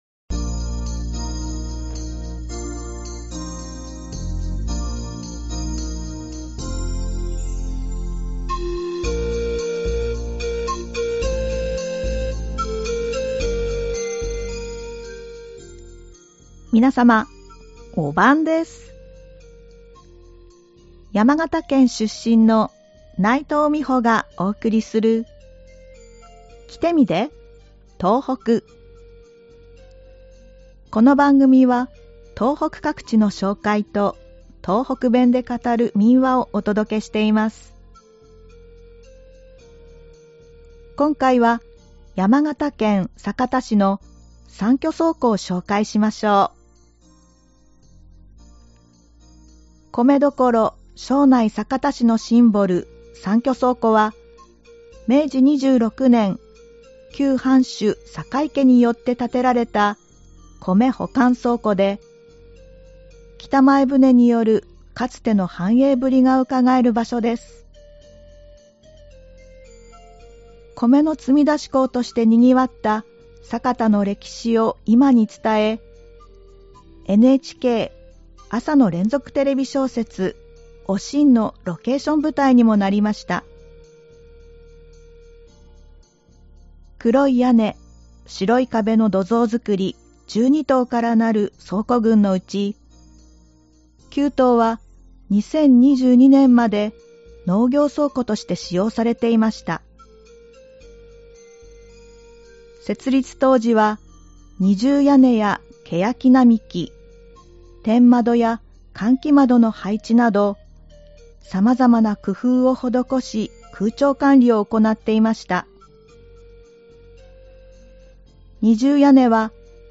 ではここから、東北弁で語る民話をお送りします。今回は山形県で語られていた民話「天狗の生き針」です。